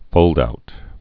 (fōldout)